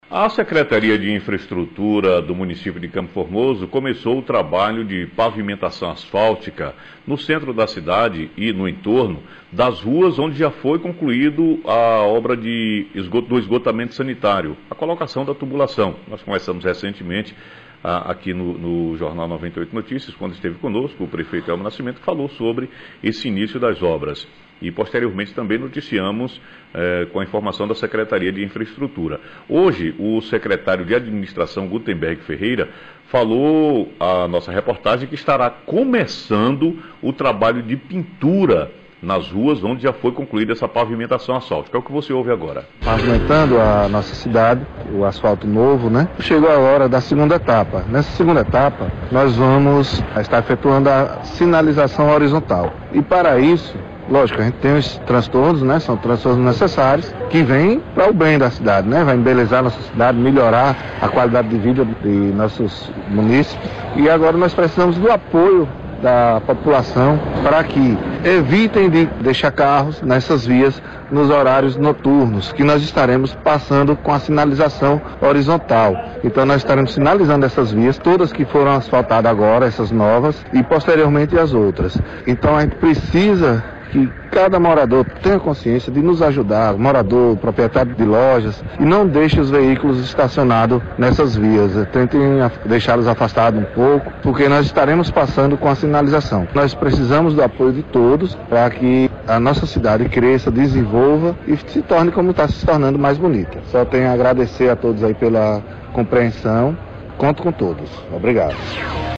Sonora: secretário de administração – Gutemberg Ferreira